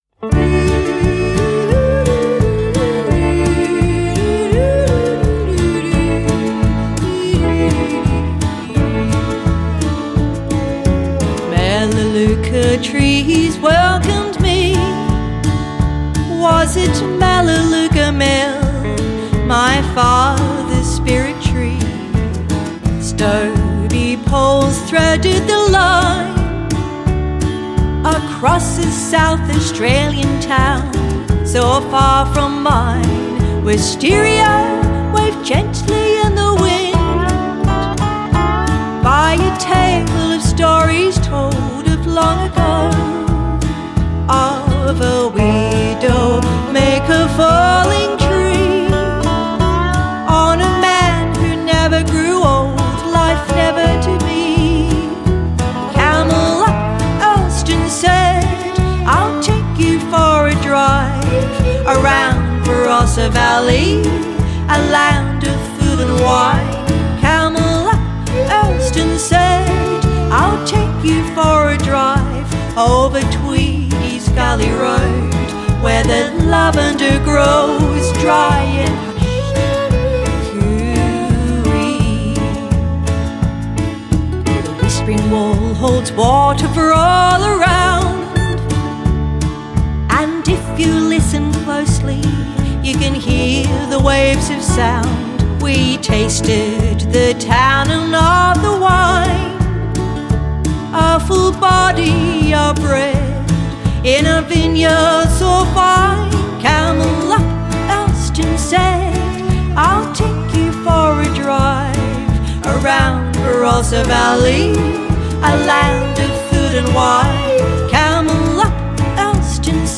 colourful ballad